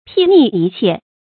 睥睨一切 注音： ㄅㄧˋ ㄋㄧˋ ㄧ ㄑㄧㄝ ˋ 讀音讀法： 意思解釋： 形容非常高傲，看不起任何人。